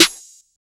Snare Goosebumps.wav